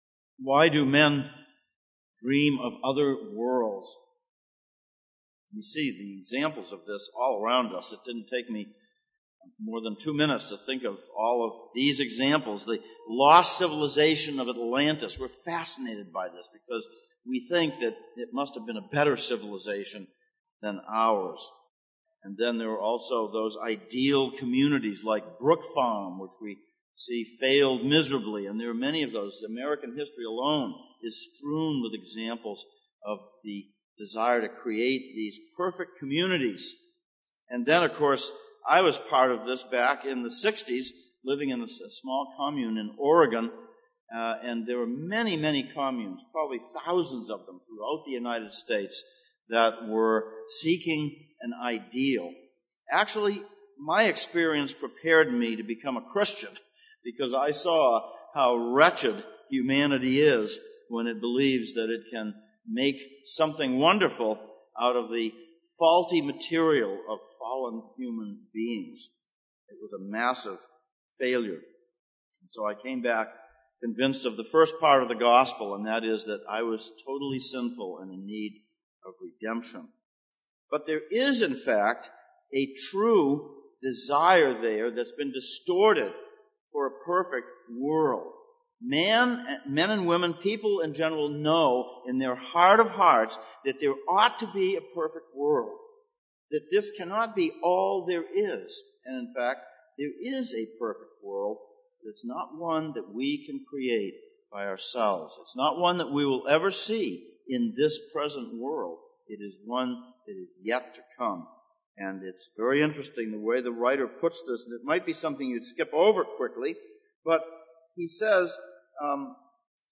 Passage: Hebrews 2:5-18, Psalm 8:1-9 Service Type: Sunday Morning